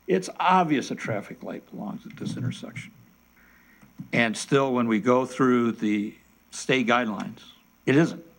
But council member Terry Urban says there is no guarantee the study will result in the installation of a traffic signal.